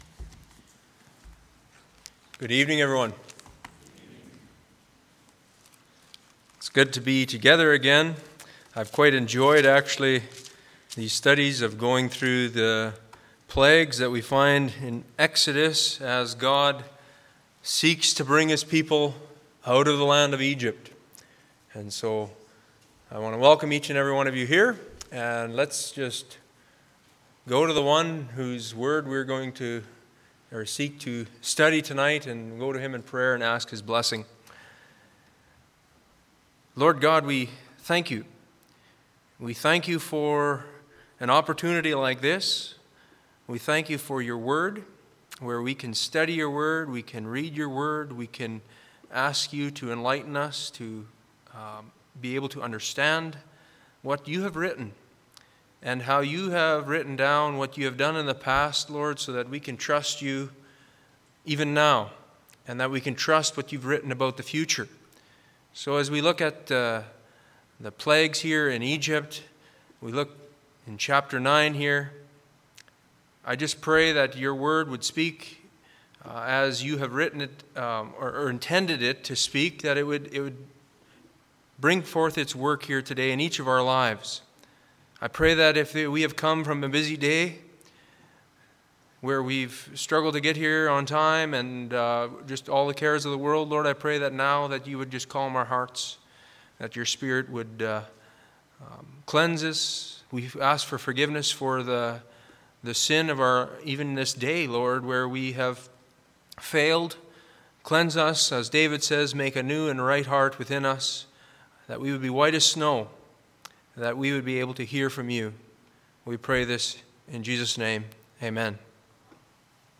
Church Bible Study – The Ten Plagues of Egypt